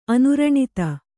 ♪ anuraṇita